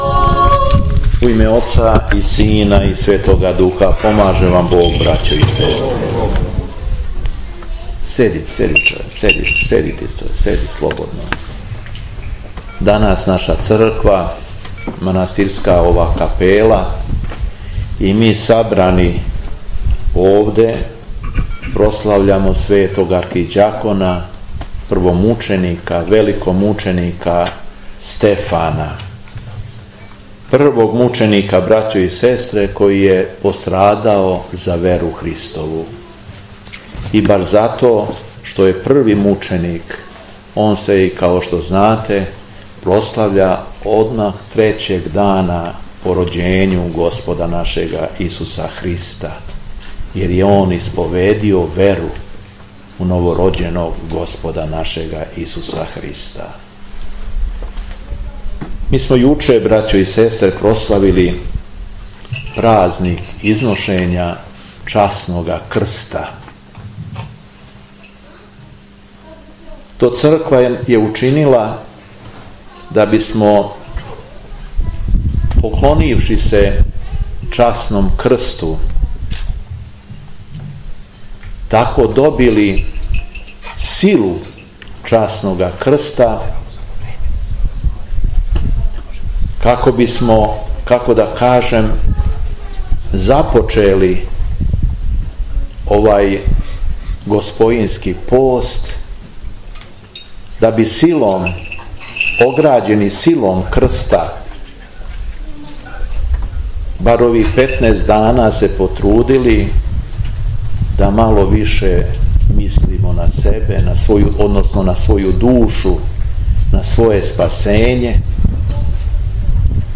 Након прочитаног Јеванђелског зачала беседио је преосвећени владика Јован. Звучни запис беседе На крају свете Литургије пресечен је славски колач у име Светог архиђакона и првомученика Стефана, а у славу Божију.